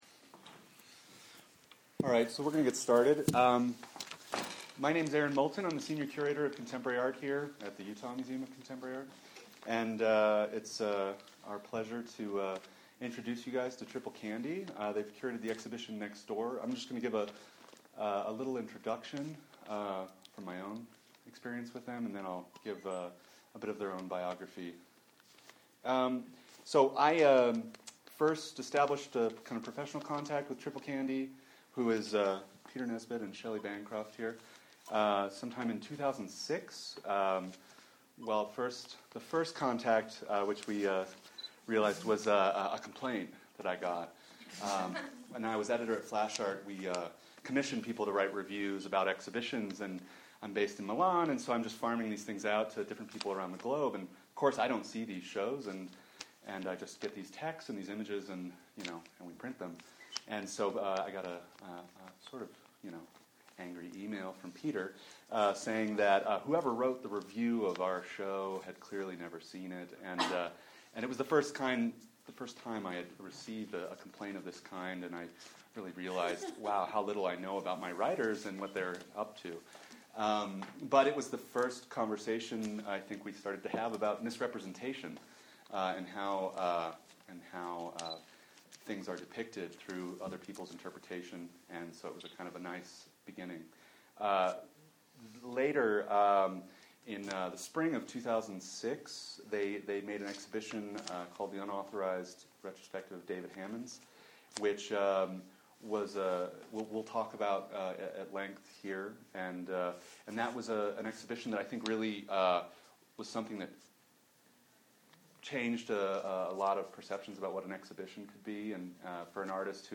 Utah Museum of Contemporary Art
Art talk